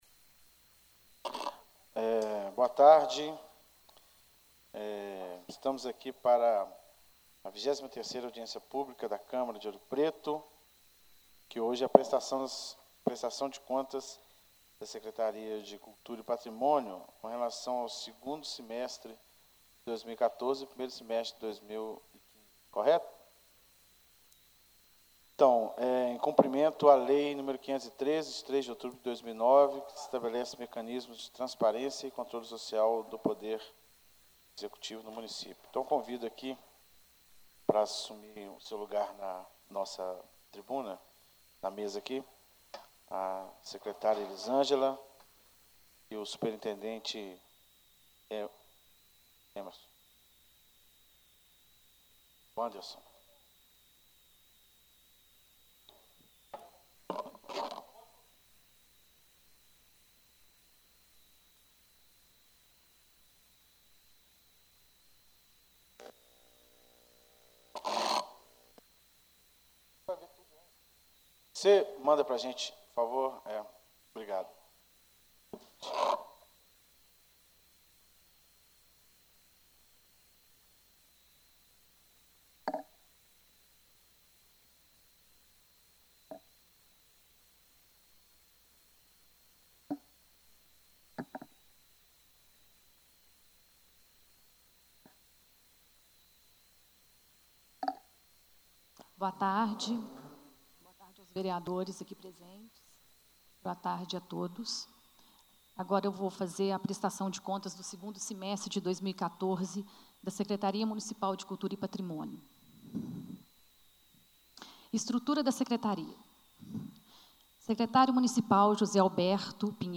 Áudio: | Câmara Municipal de Ouro Preto Audiência Pública: Prestação de Contas da Secretaria de Cultura e Patrimônio, referente ao 2° semestre de 2014 e 1° semestre de 2015 Reunião Compartilhar: Fechar